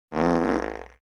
Soundmaschine, Soundgenerator, Gadget. Das perfekte Geschenk, Wichtelgeschenk, Scherzartikel für Weihnachten, Ostern, Geburtstag und zwischendurch